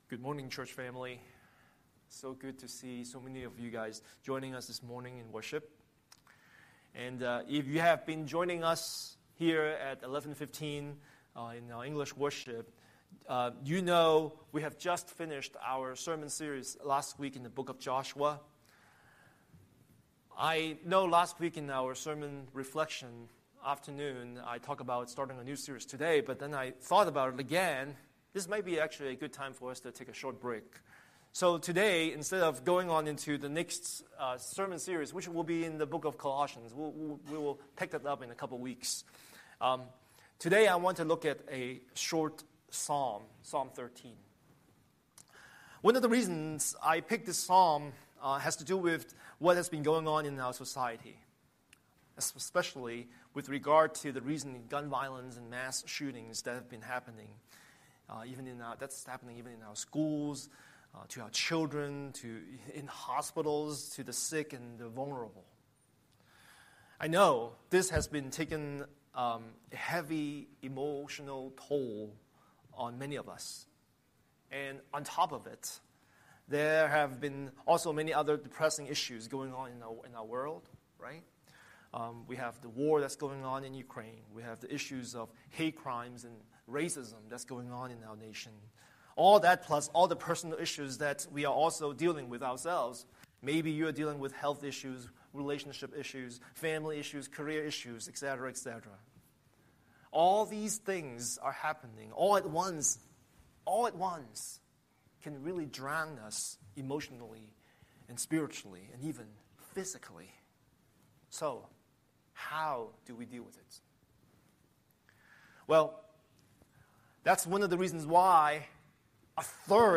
Scripture: Psalm 13:1–6 Series: Sunday Sermon